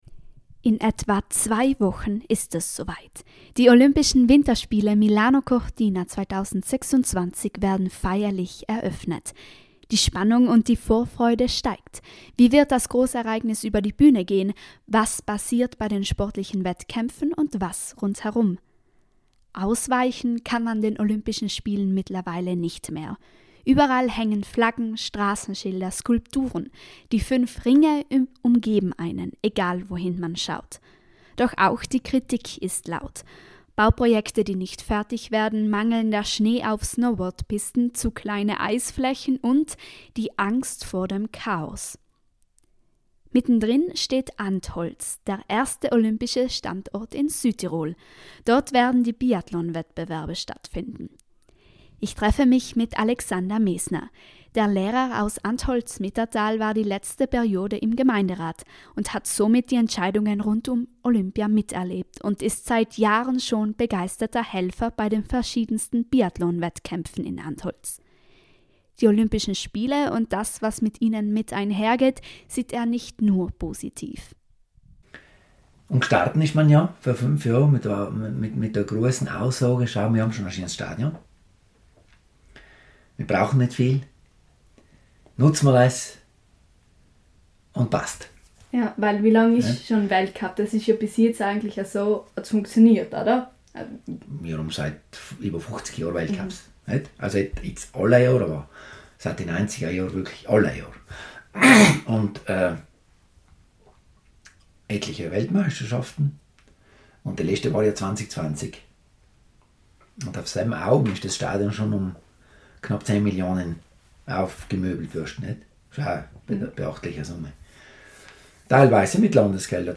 Einerseits ist die Vorfreude in der Region groß, andererseits mehren sich jedoch auch kritische Stimmen. Im Gespräch